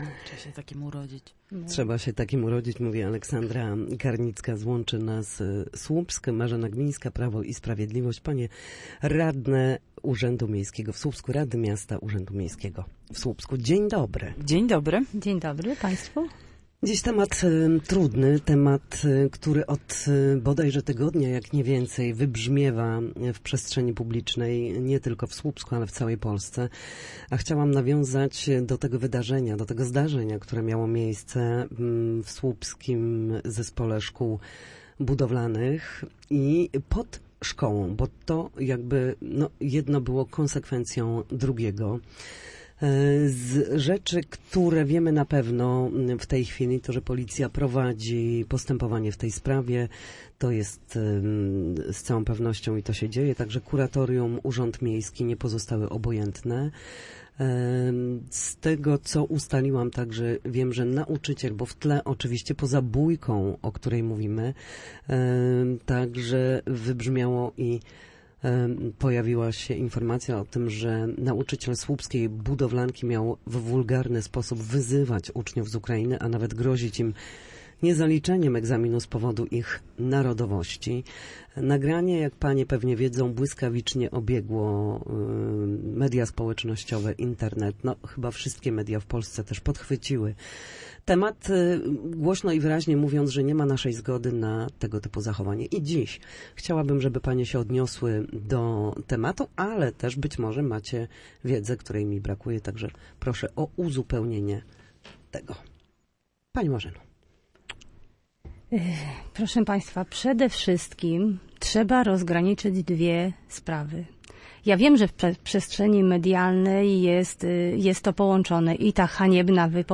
Gośćmi Studia Słupsk były radne Słupska Aleksandra Karnicka z klubu Łączy nas Słupsk oraz Marzena Gmińska z Prawa i Sprawiedliwości.